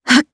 Ripine-Vox_Casting1_jp.wav